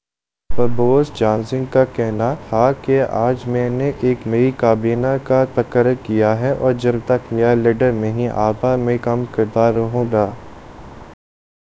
Spoofed_TTS/Speaker_09/258.wav · CSALT/deepfake_detection_dataset_urdu at main